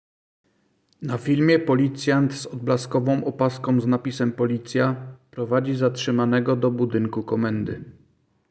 Nagranie audio audiodeskrypcja_kradziez.m4a